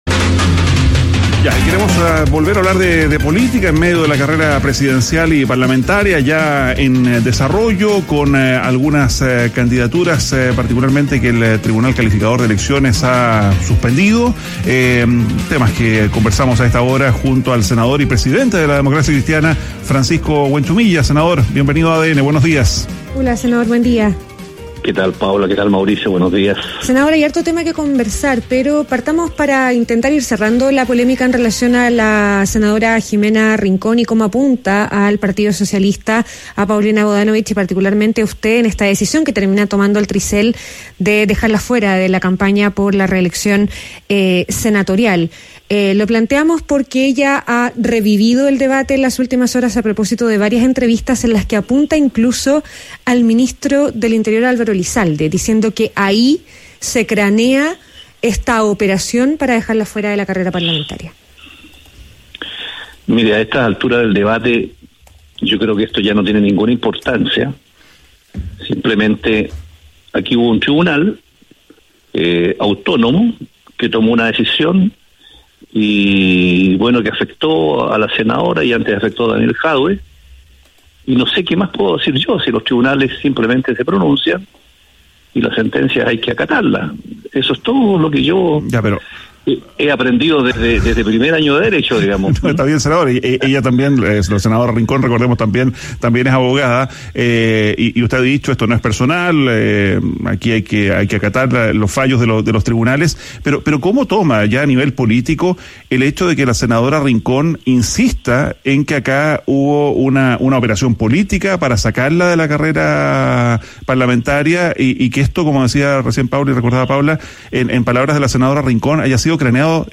ADN Hoy - Entrevista a Francisco Huenchumilla, presidente de la Democracia Cristiana